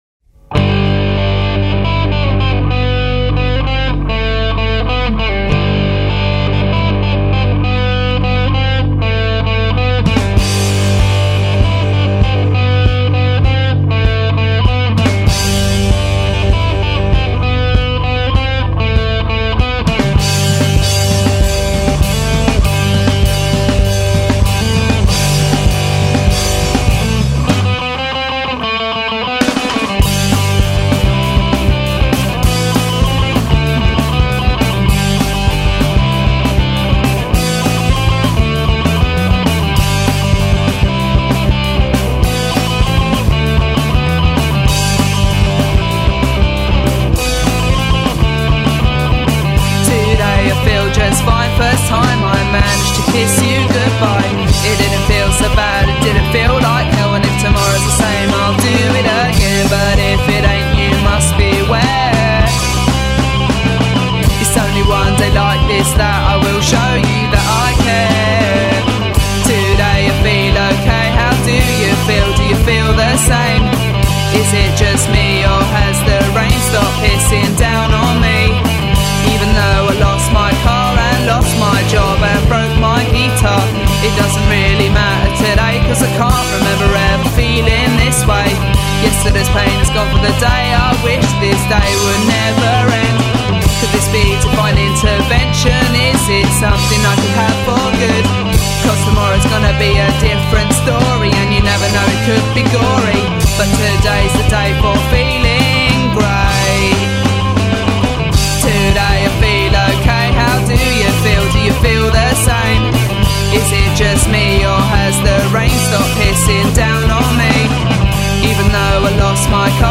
songwriter, guitarist, bassist & drummer
catchy "sing along" choruses and melodic guitars
"Punk" with a positive vibe and direction